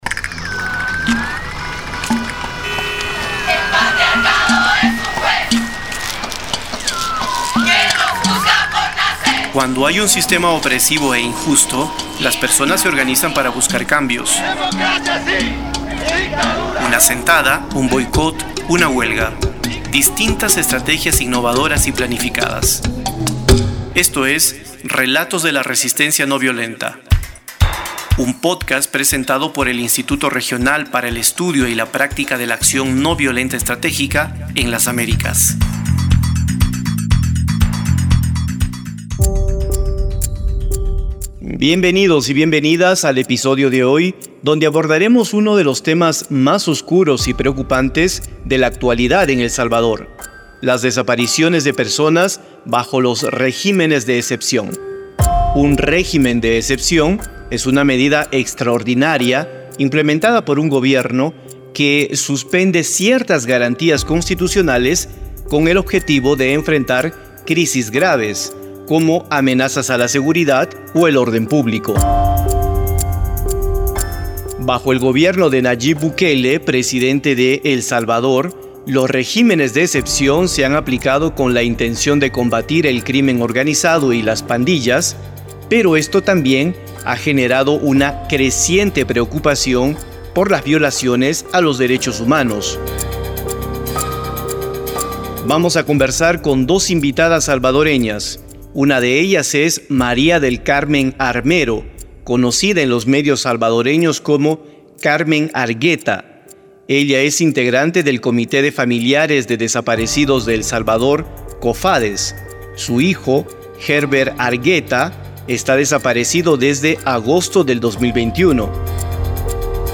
Entrevista - Acción Noviolenta